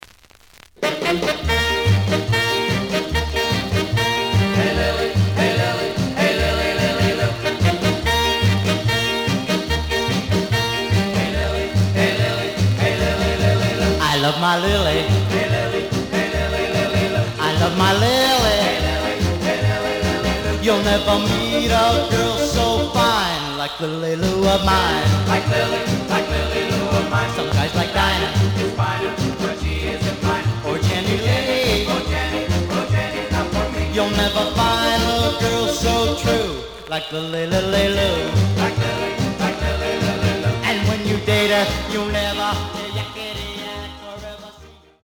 The listen sample is recorded from the actual item.
●Genre: Rhythm And Blues / Rock 'n' Roll